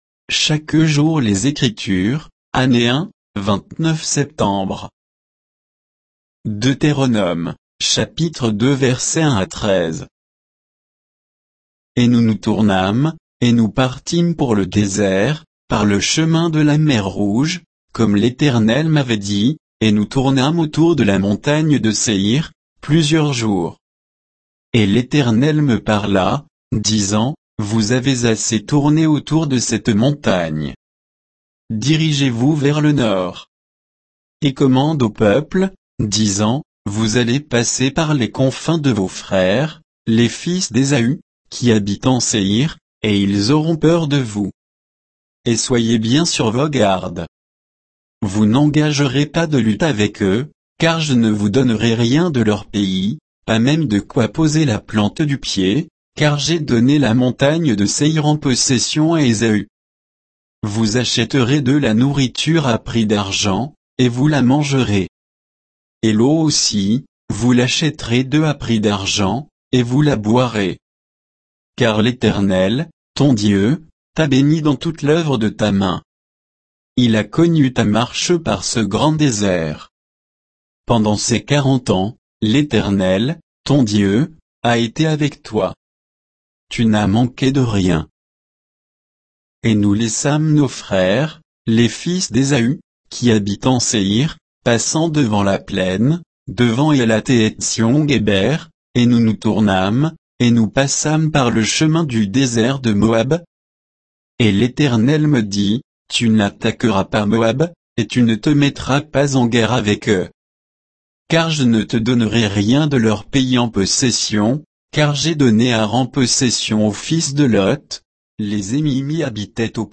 Méditation quoditienne de Chaque jour les Écritures sur Deutéronome 2, 1 à 13